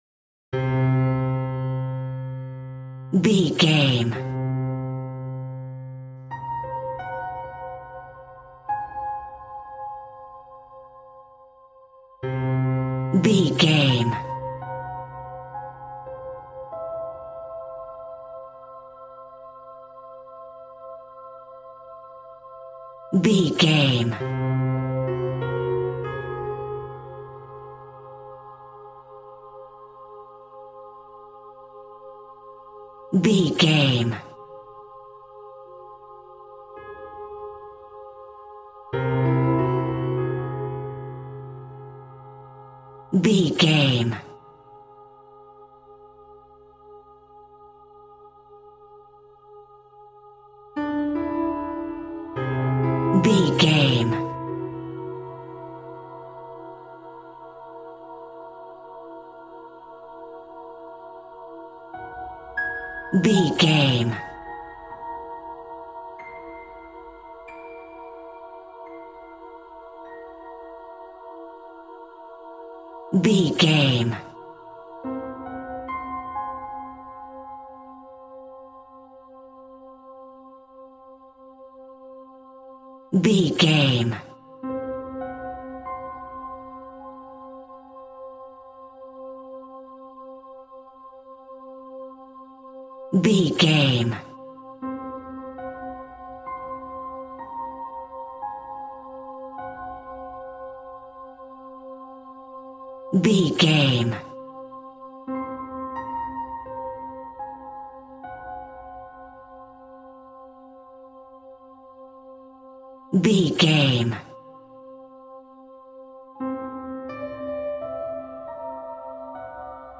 Aeolian/Minor
Slow
scary
ominous
dark
haunting
eerie
piano
strings
synth
ambience
pads